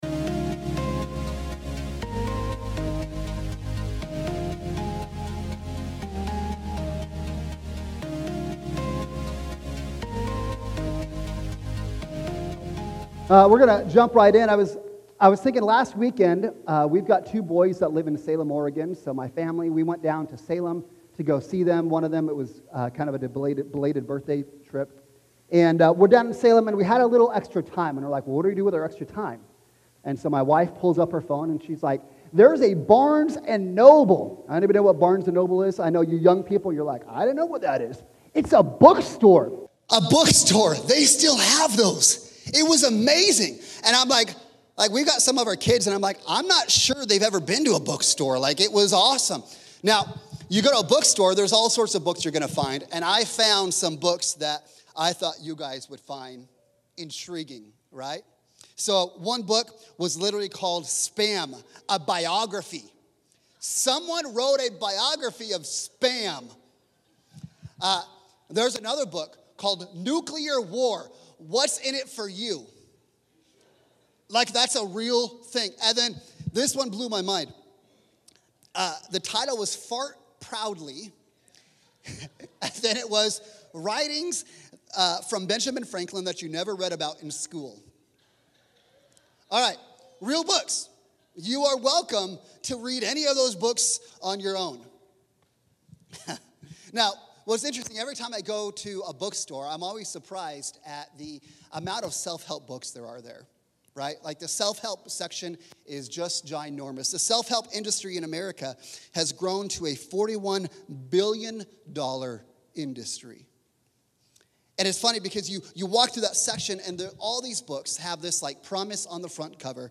sabbath-sermon-audio.mp3